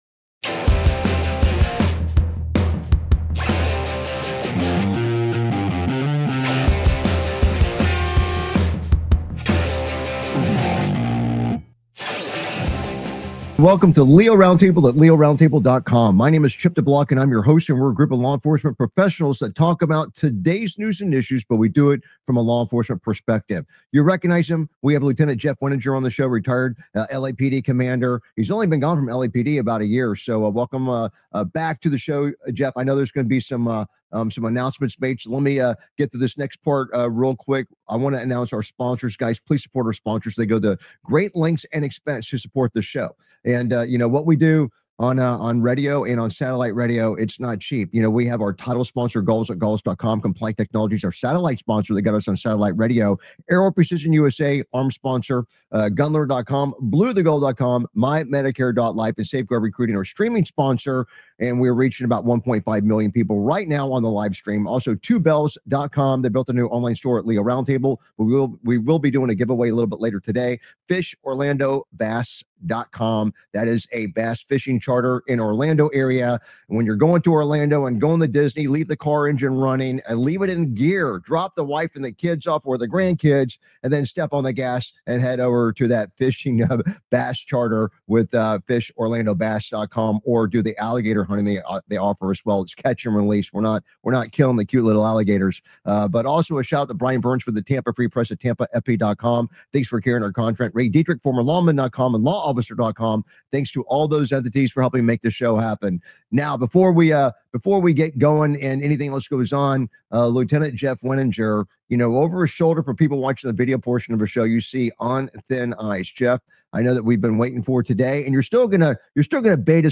LEO Round Table is a nationally syndicated law enforcement satellite radio talk show discussing today's news and issues from a law enforcement perspective.
Their panelists are among a Who's Who of law enforcement professionals and attorneys from around the country.